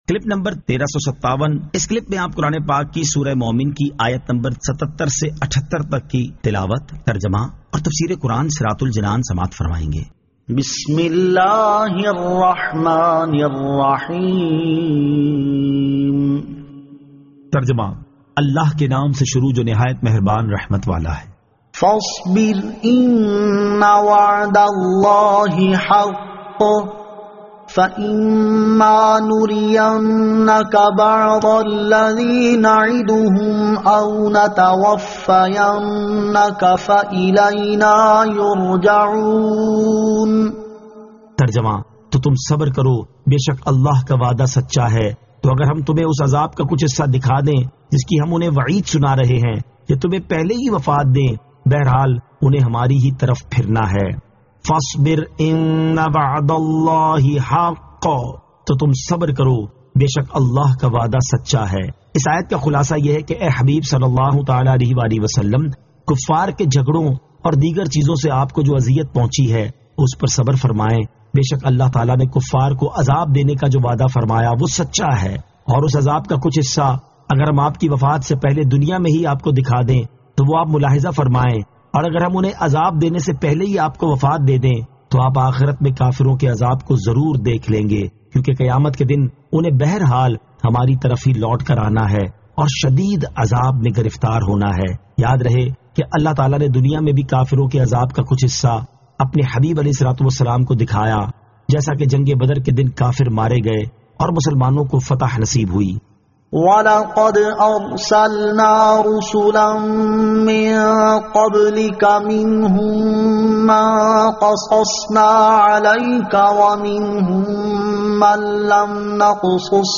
Surah Al-Mu'min 77 To 78 Tilawat , Tarjama , Tafseer